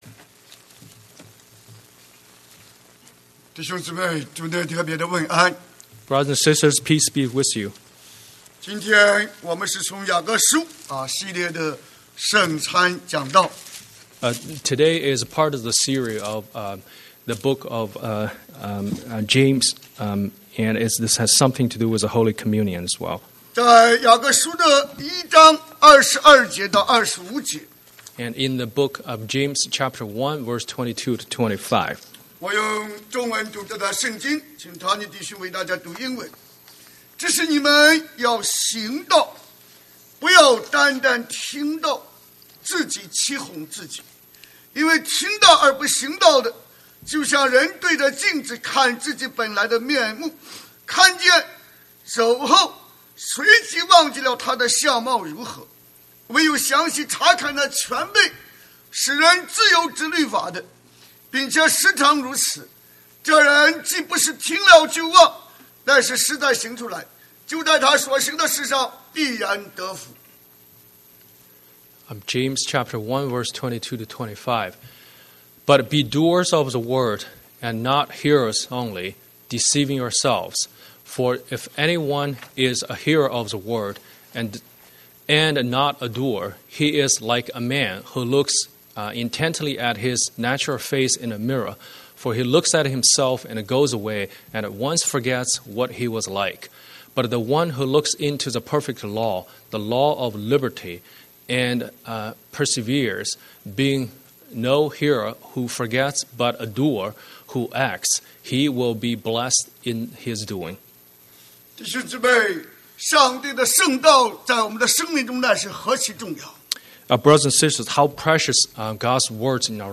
Scripture: Luke 2:25-35 Series: Sunday Sermon